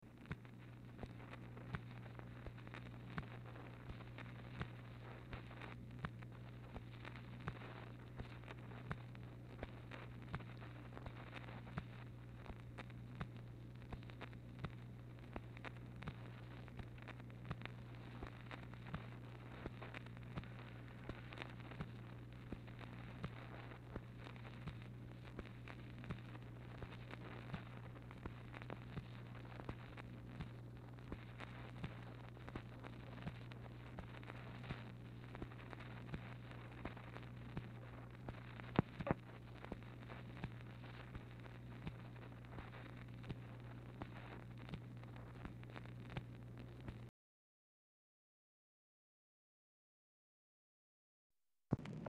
MACHINE NOISE
LBJ Ranch, near Stonewall, Texas
Telephone conversation
Dictation belt